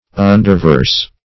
Underverse \Un"der*verse`\, n.